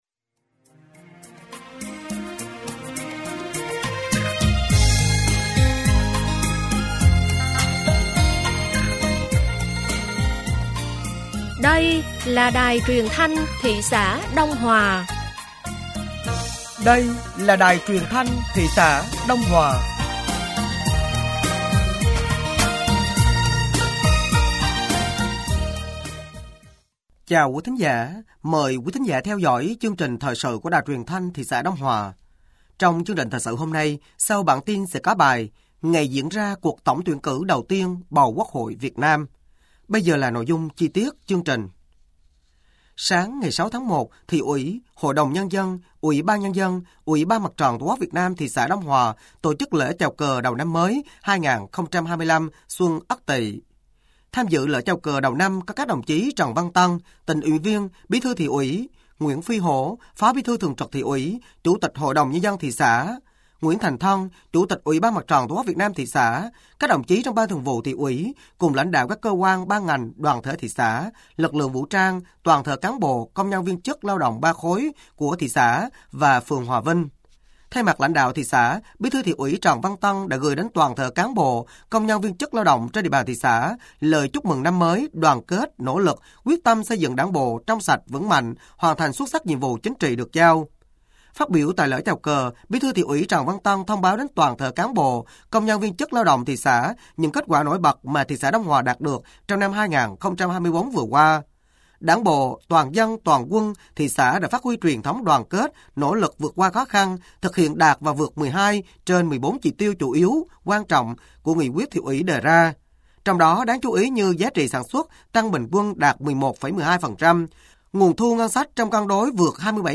Thời sự tối ngày 06 và sáng ngày 07 tháng 01 năm 2024